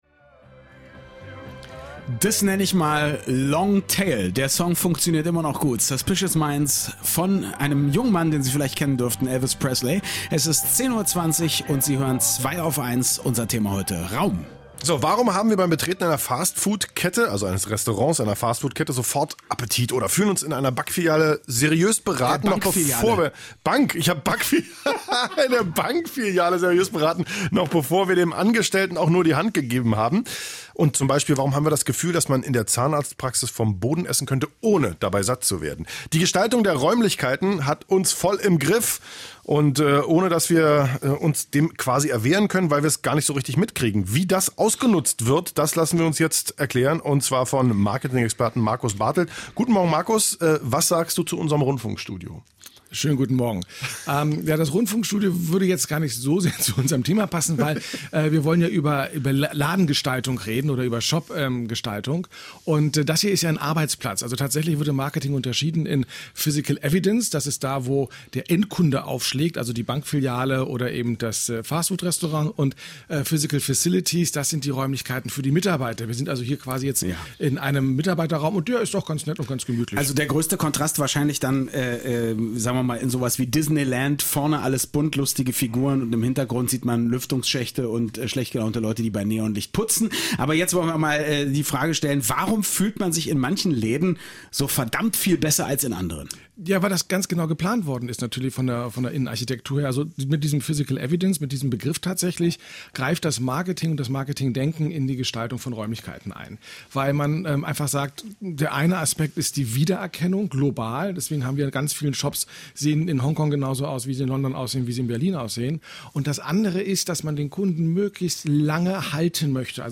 Ich war in#s „radioeins„-Studio geladen, um ein wenig den Marketingaspekt von Räumen, insbesondere vom POS (point of sale) zu beleuchten, also dem Ort, an dem Dienstleistungen erbracht werden.